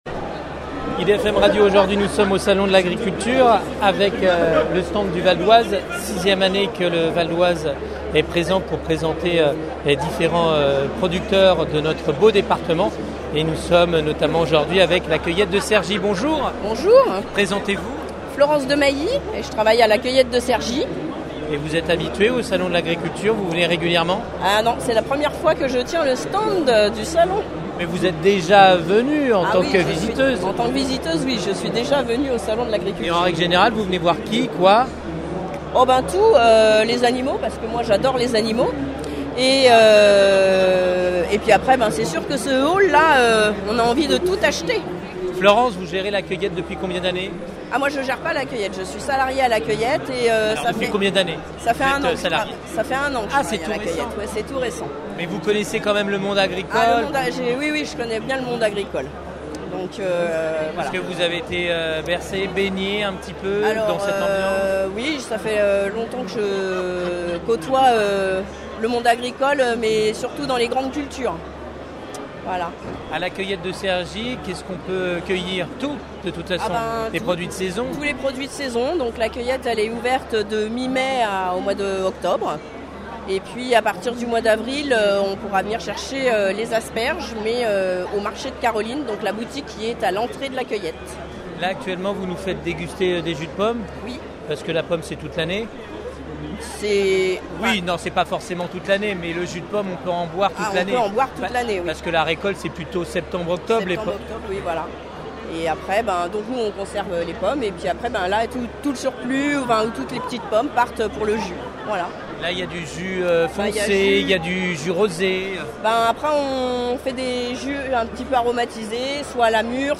Itw-SALON-AGRI-Bon-sans-les-separations.mp3